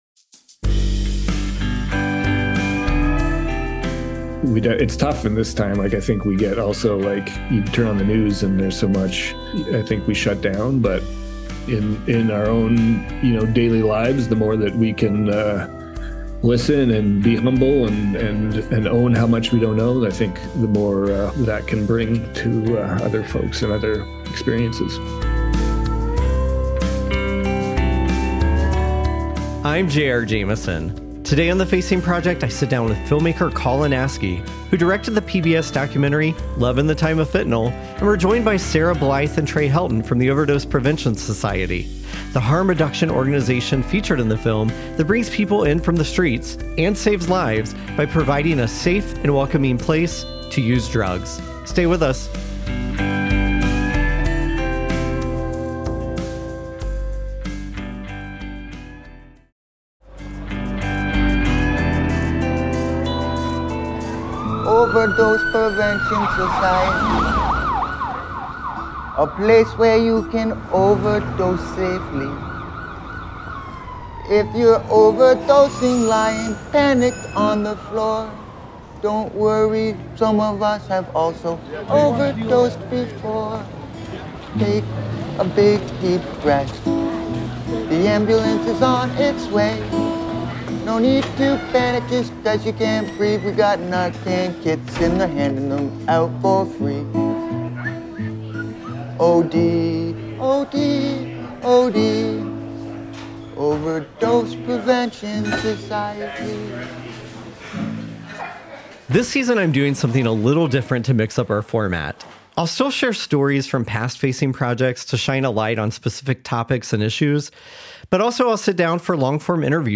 A conversation about how providing a safe and welcoming place to use drugs saves lives.